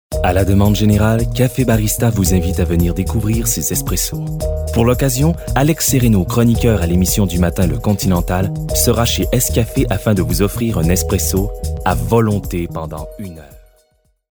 Timbre Médium - Grave
Café Barista - Suave - Souriant - Français soutenu /